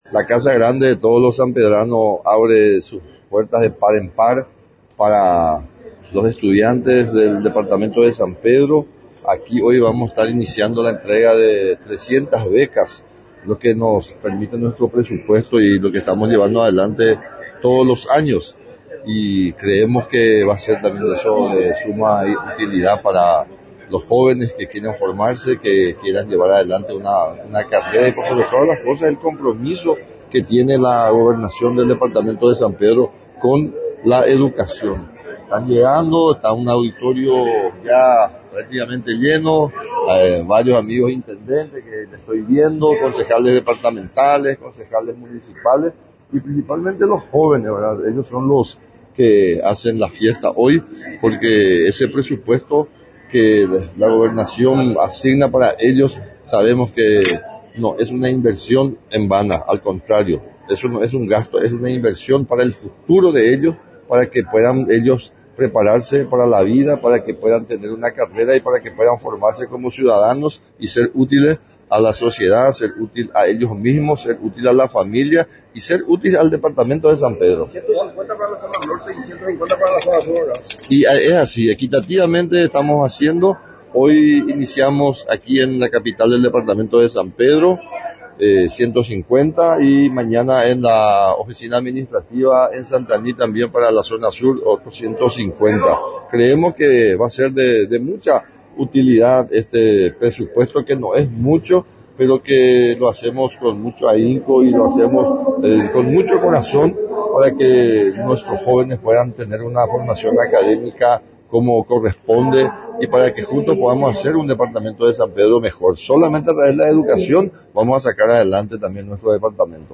NOTA: FREDDY DECCLESIIS-GOBERNADOR DE SAN PEDRO.